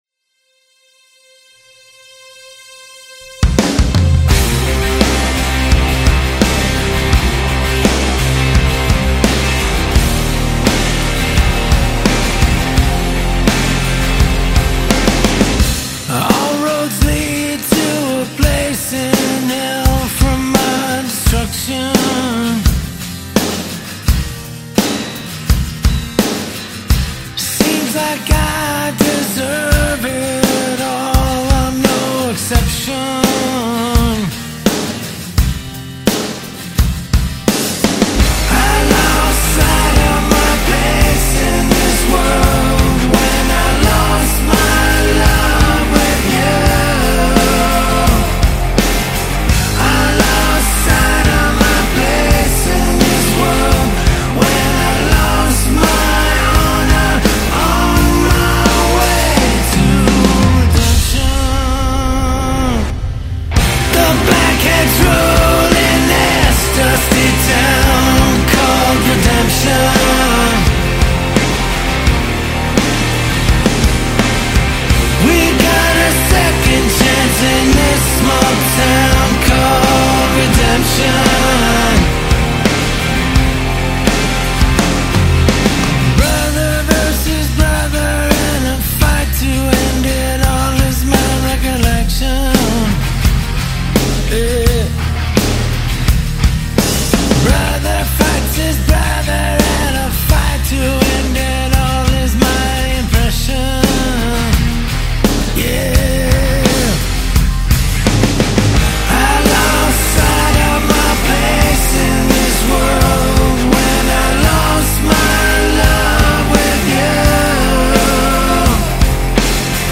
industrial grit